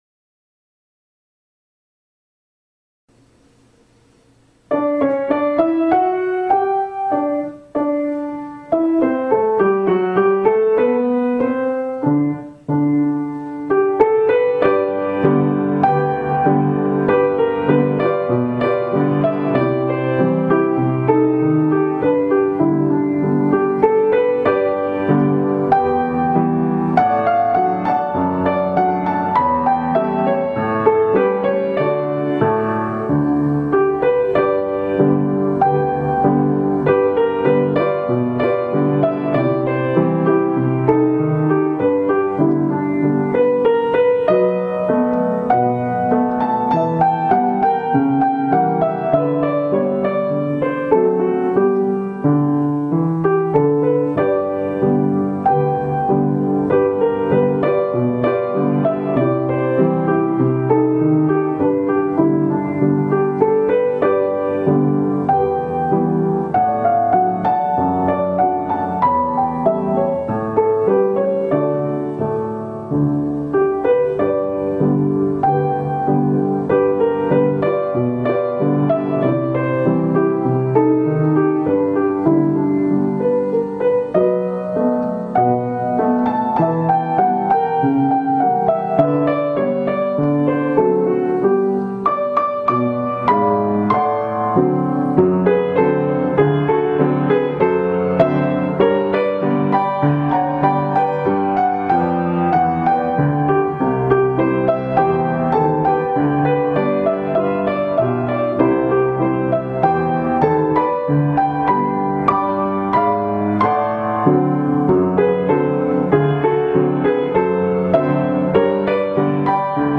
O piano era seu grande amigo.